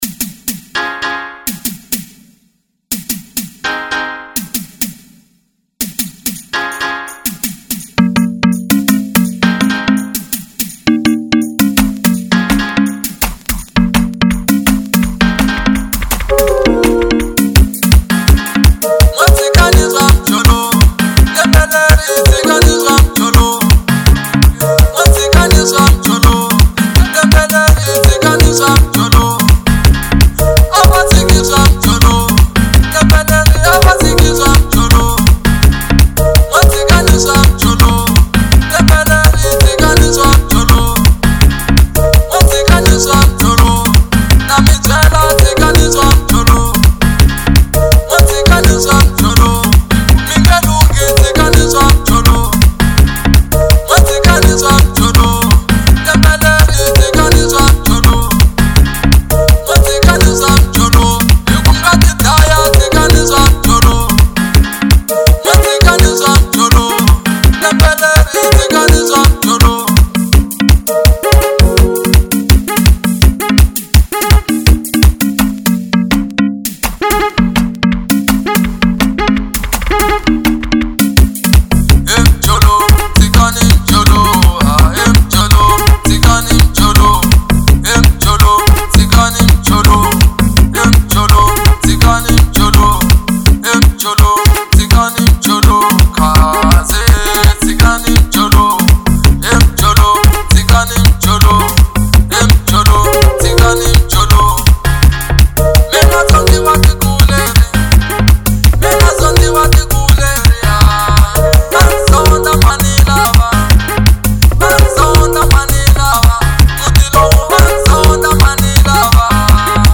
04:08 Genre : Xitsonga Size